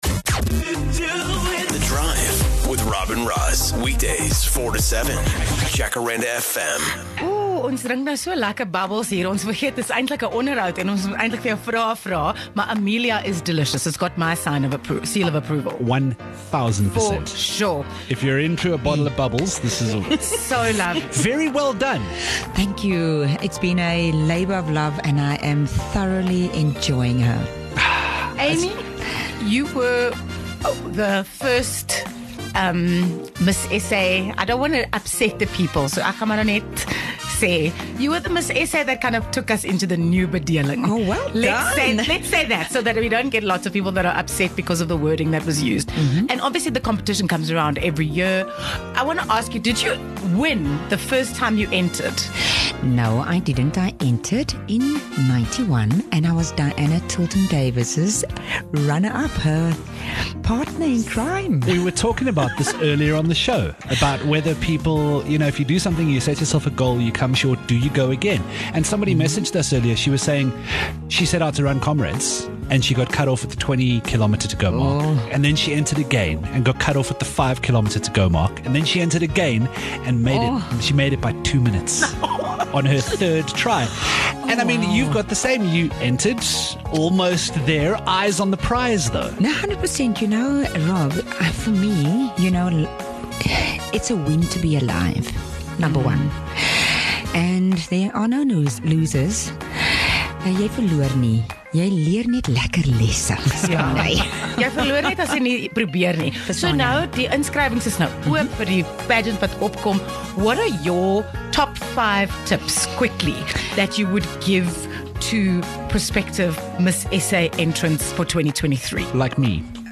The first non-white Miss South Africa, Amy Kleinhans-Curd, recently joined the latest local Real Housewives production but while visiting thee studio we just had to ask her what advice she has for anyone hoping to enter, and win, the Miss SA 2023 competition.